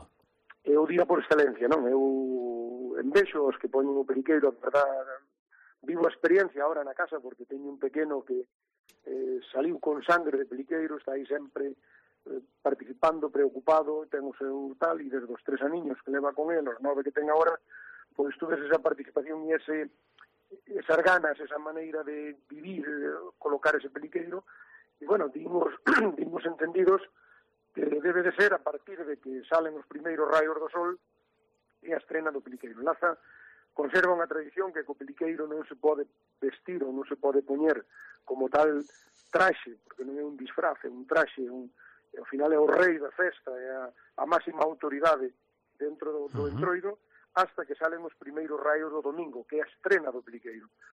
El alcalde de Laza explica la emoción entre los vecinos el "Domingo de Estrea" del Peliqueiro